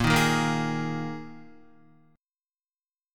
Bb7sus4 chord